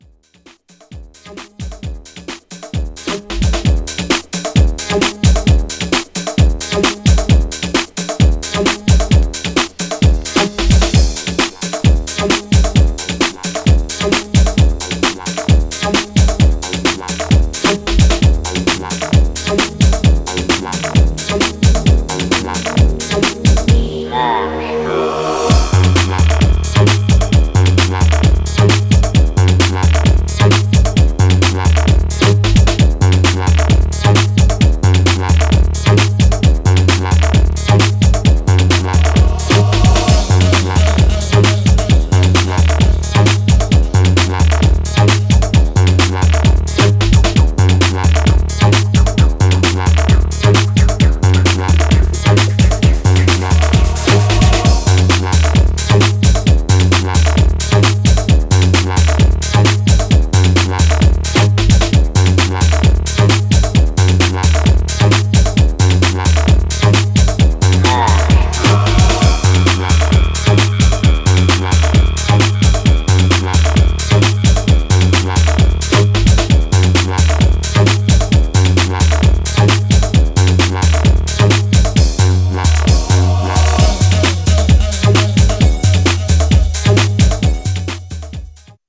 Styl: Breaks/Breakbeat